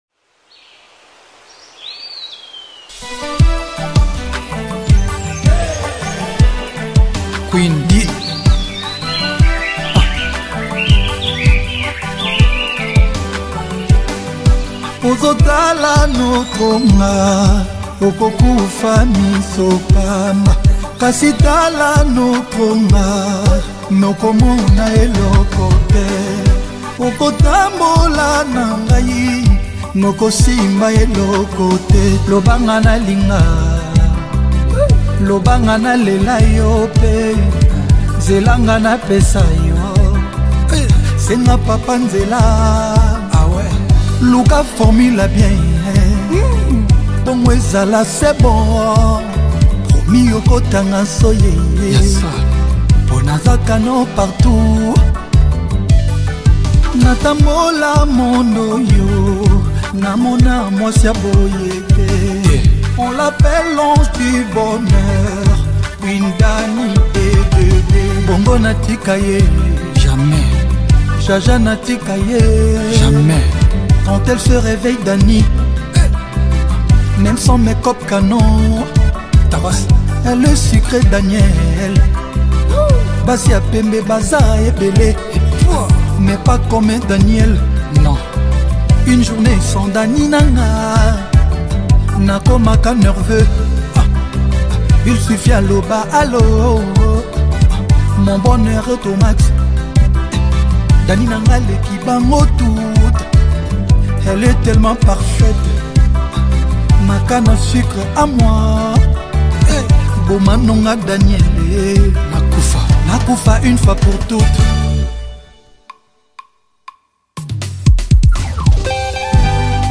| Afro Congo